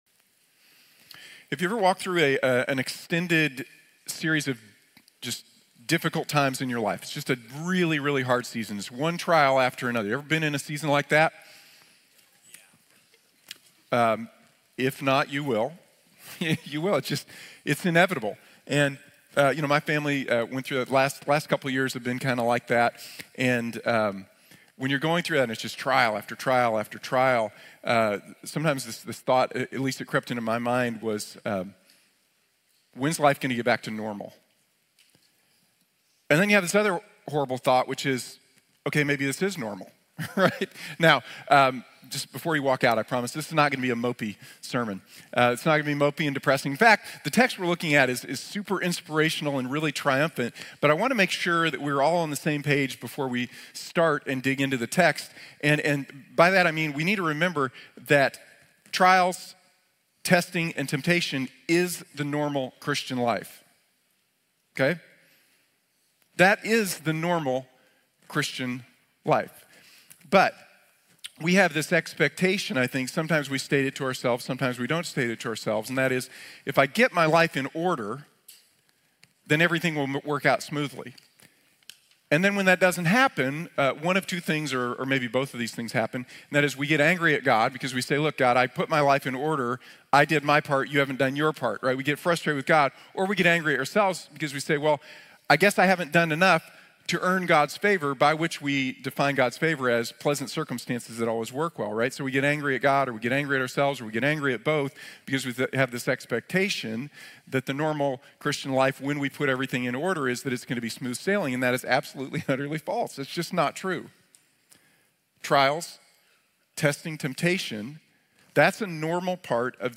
Battle Like Jesus | Sermon | Grace Bible Church